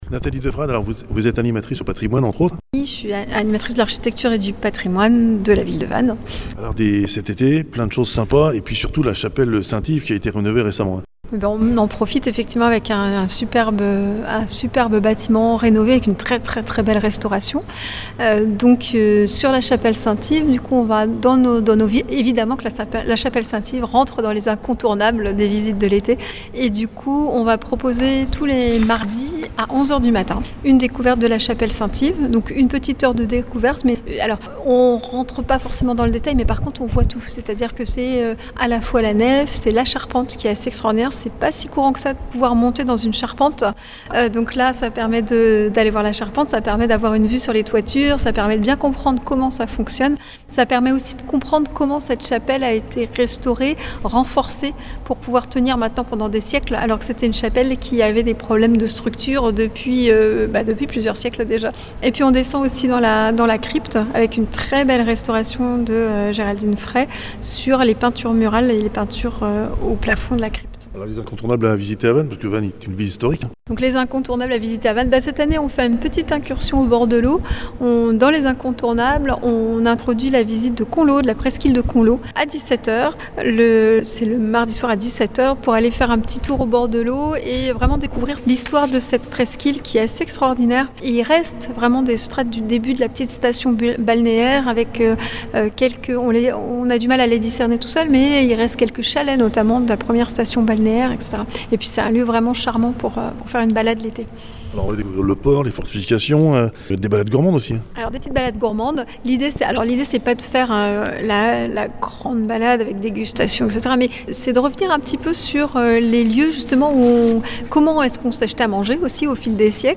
Interview Radio Korrigans, Radio Larg, Radio Balises…